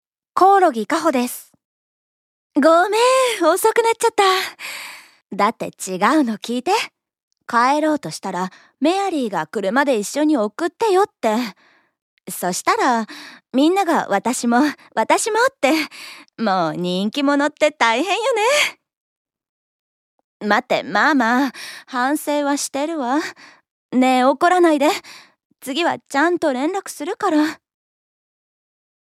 ◆10代女子(明るい)◆